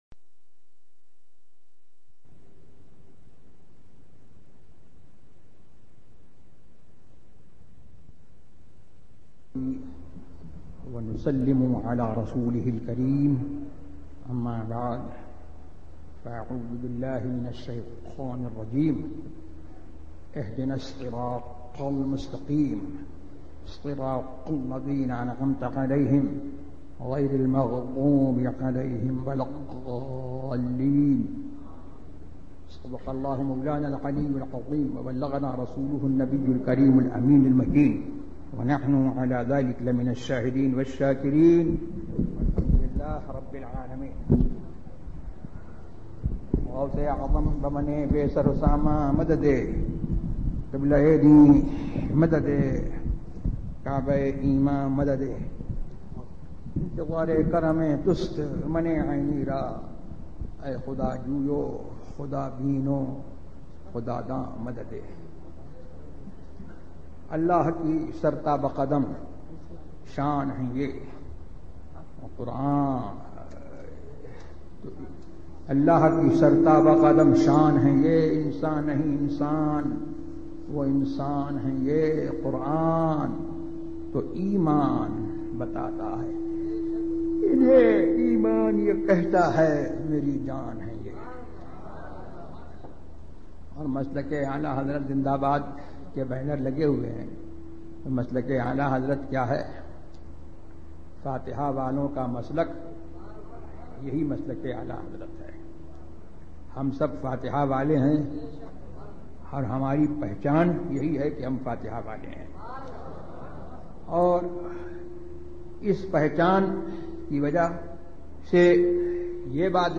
موضوع تقاریر آواز تاج الشریعہ مفتی اختر رضا خان ازہری زبان اُردو کل نتائج 1073 قسم آڈیو ڈاؤن لوڈ MP 3 ڈاؤن لوڈ MP 4 متعلقہ تجویزوآراء